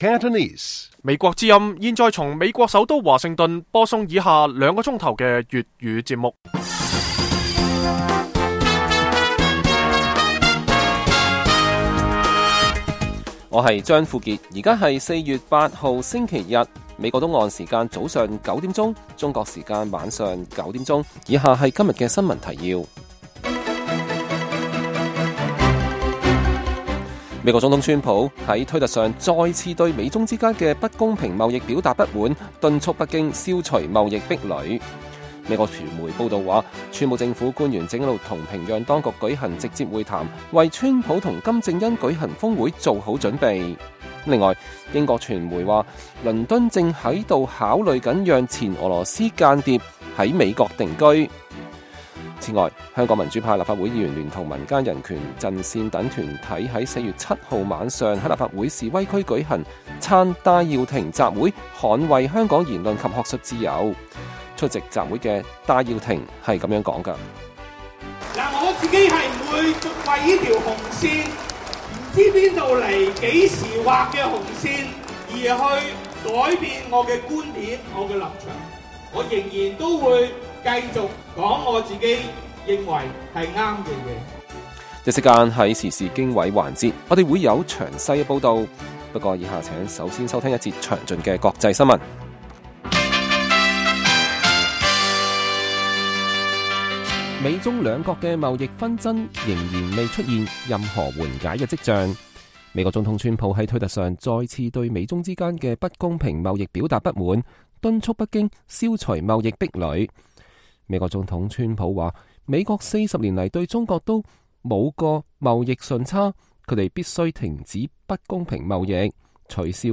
粵語新聞 晚上9-10點
北京時間每晚9－10點 (1300-1400 UTC)粵語廣播節目。內容包括國際新聞、時事經緯和英語教學。